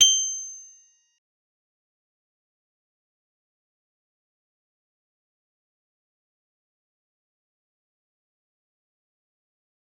G_Musicbox-G8-mf.wav